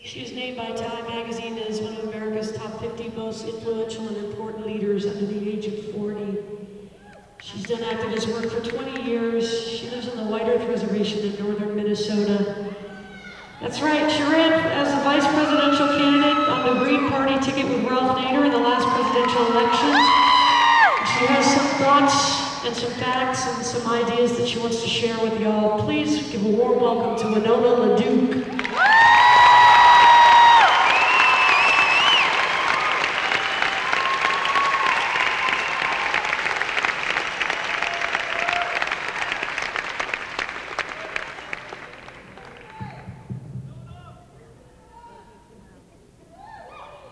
lifeblood: bootlegs: 1997-09-10: the palace theater - albany, new york (honor the earth)
01. emily introduces winona laduke (0:47)